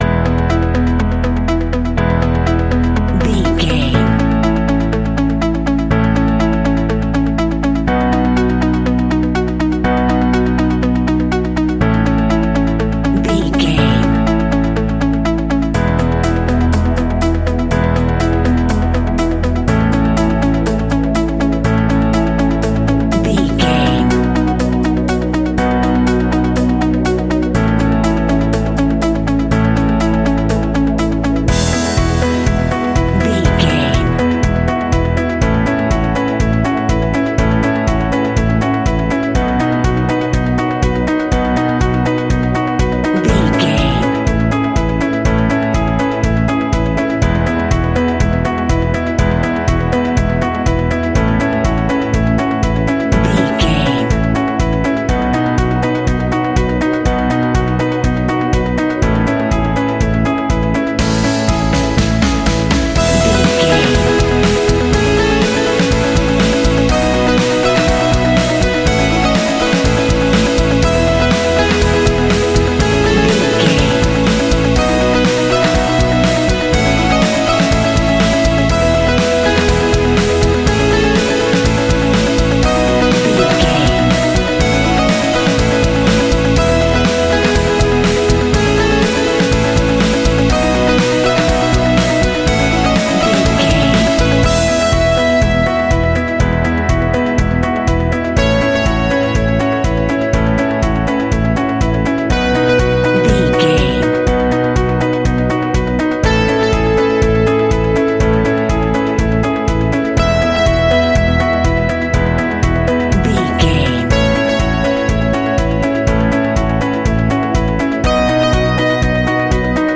Ionian/Major
Fast
calm
energetic
uplifting
piano
electric guitar
bass guitar
drums
pop rock
indie pop
instrumentals
organ